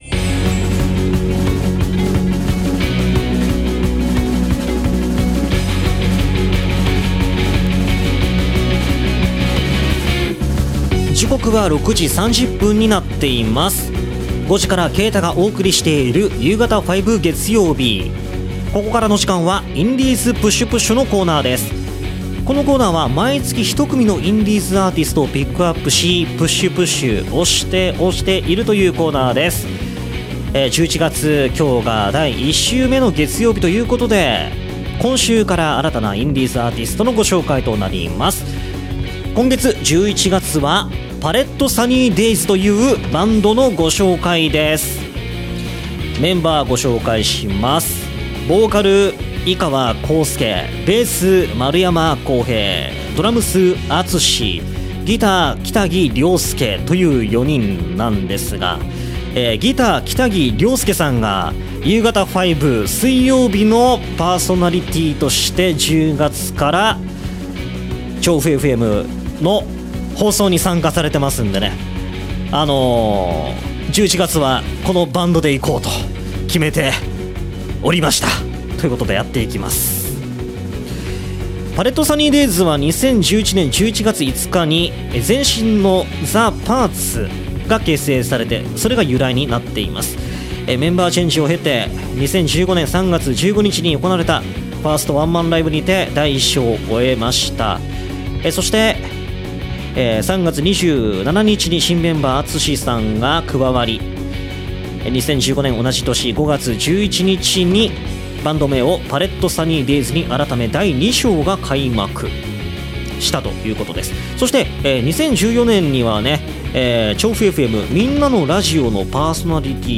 今回の放送同録音源はこちら↓